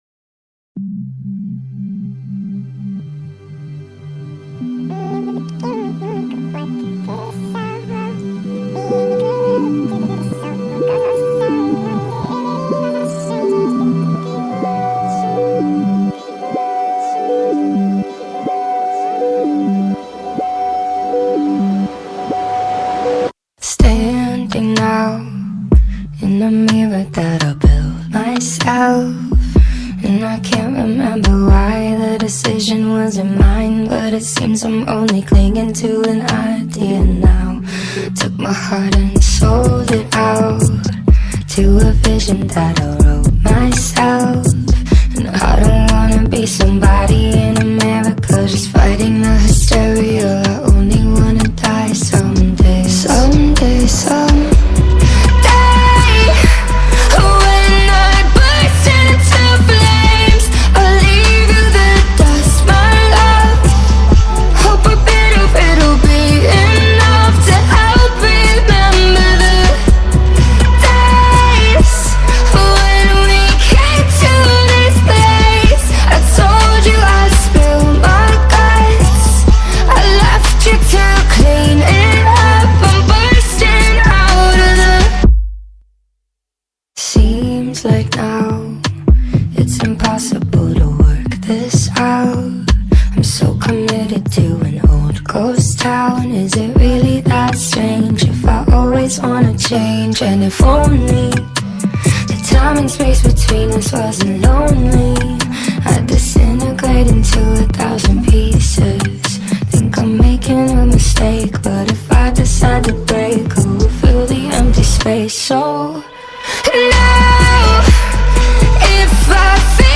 Pop / Alternative